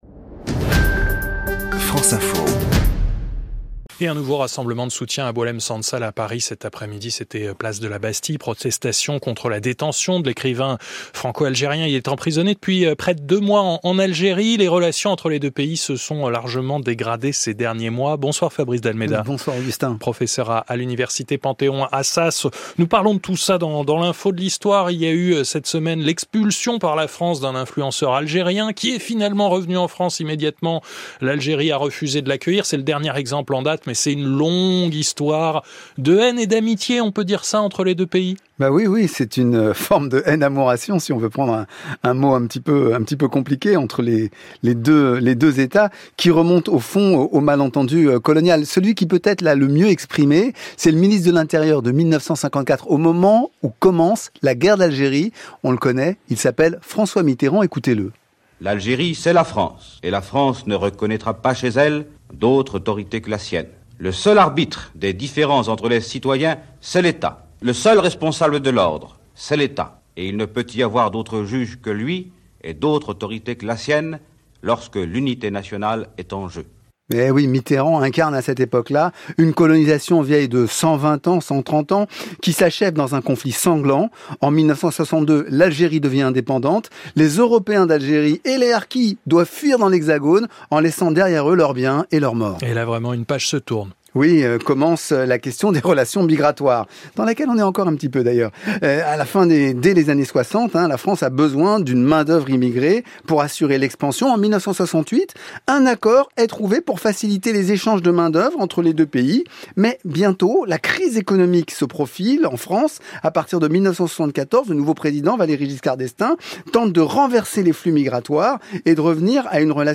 Chaque samedi, sur France-Inter, l’historien Fabrice d’Almeida remet en perspective l’actualité immédiate. Le 11 janvier, il s’emparait du problème brûlant des relations tumultueuses entre la France et l’Algérie.